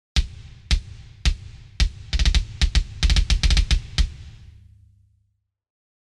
Mix-ready metal drums with massive impact
Punchy and precise in the mix
This Kontakt instrument delivers monumental metal beats: massive punch, analog warmth, and digital precision. Drums that are perfectly suited to supporting low-tuned modern guitars.